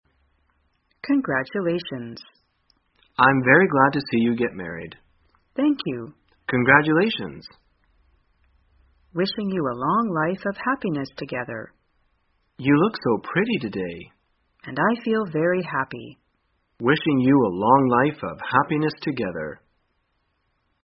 在线英语听力室生活口语天天说 第278期:怎样祝福婚姻的听力文件下载,《生活口语天天说》栏目将日常生活中最常用到的口语句型进行收集和重点讲解。真人发音配字幕帮助英语爱好者们练习听力并进行口语跟读。